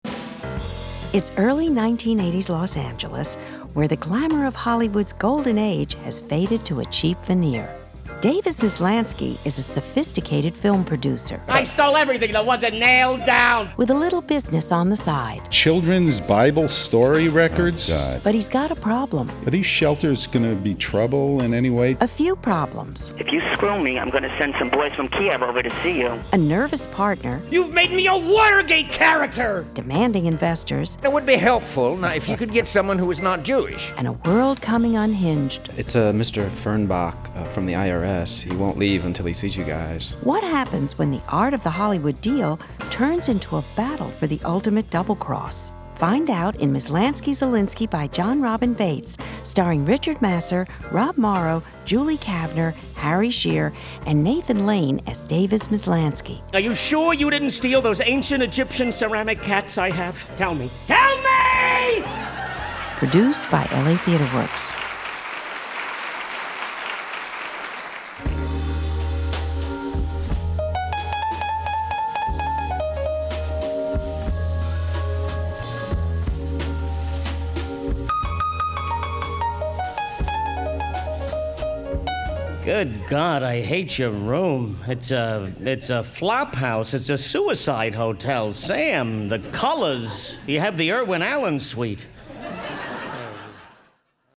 They demonstrate how each hour of L. A. Theatre Works can either stand alone or be broadcast together.  Hour Two is only the first 1:35 intro and shows how the two hours would connect to each other.